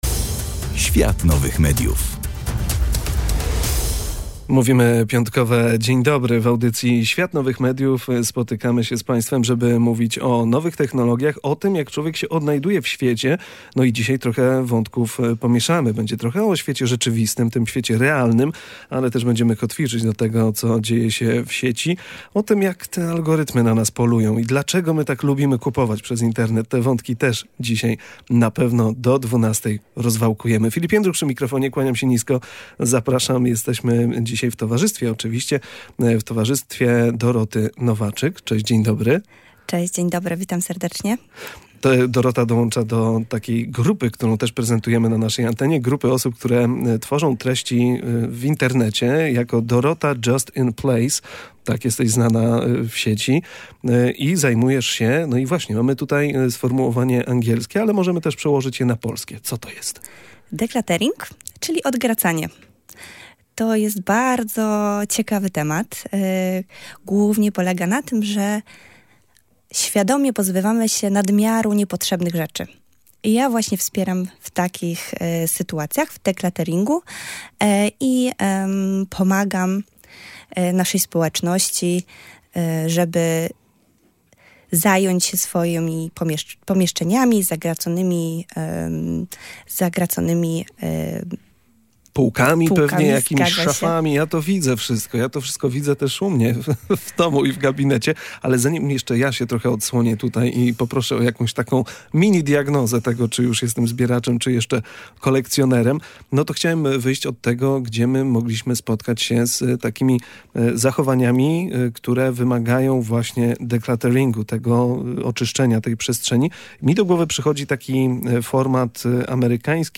W rozmowie poruszamy także temat wpływu algorytmów i łatwości zakupów internetowych na nasze otoczenie i decyzje – co przekłada się na to, że wokół nas jest coraz więcej rzeczy. Przez pryzmat nowych technologii spojrzeliśmy na codzienne nawyki i pułapki współczesnego konsumpcjonizmu.